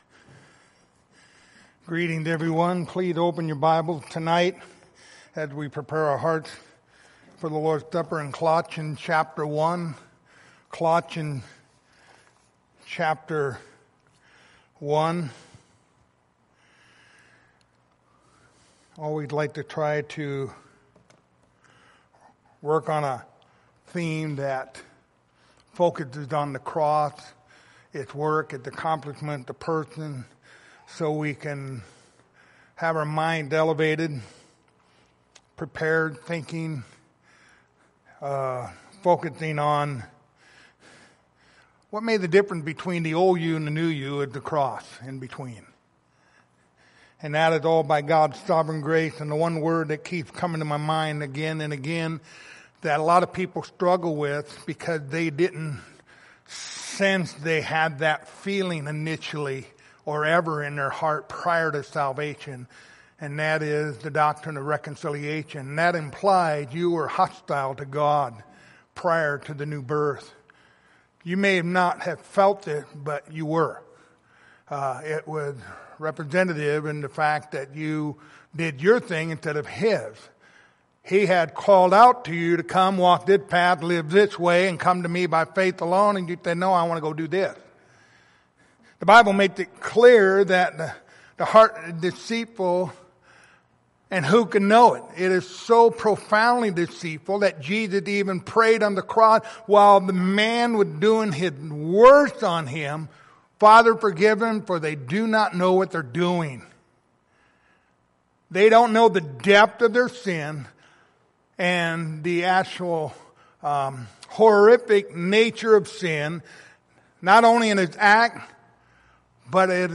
Colossians 1:20-22 Service Type: Lord's Supper Topics